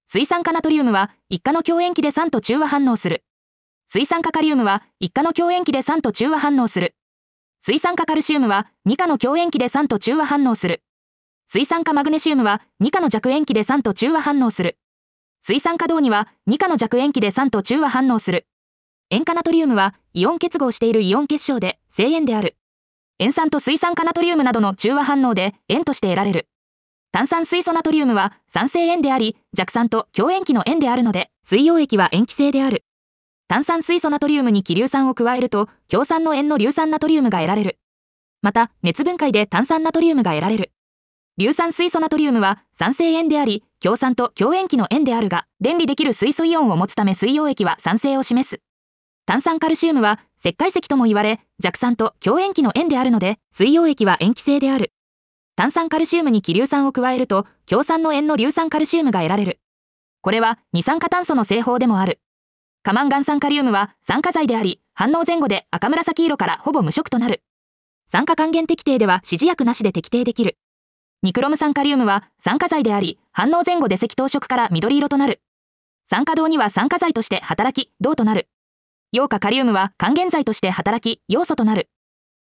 • 耳たこ音読では音声ファイルを再生して要点を音読します。通学時間などのスキマ学習に最適です。
ナレーション 音読さん